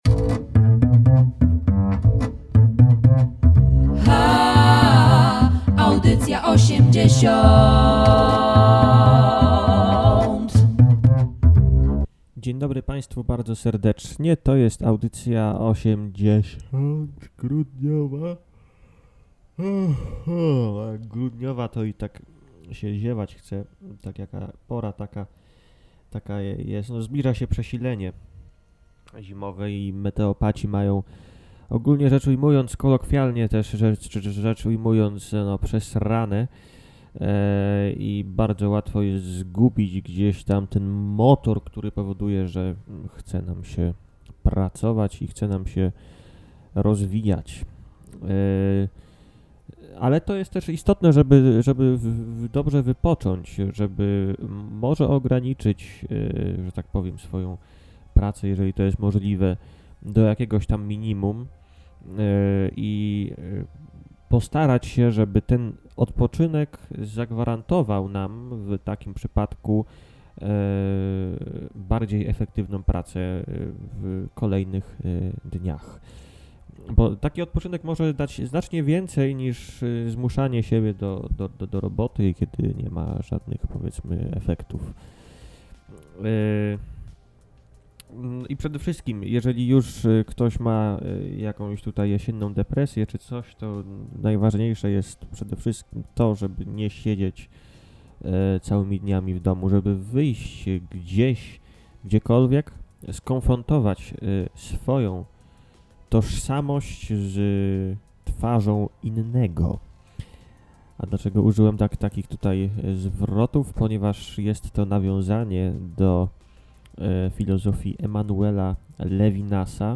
Co było sednem jego filozofowania? Z odpowiedziami na te pytania przyszła nam Maskacjusz TV z wykładem rektorskim abp. Jędraszewskiego na Uniwersytecie Łódzkim.
Każda audycja obfituje w masę niekonwencjonalnej muzyki, granej przez wyjątkowych, aczkolwiek bardzo często niszowych artystów.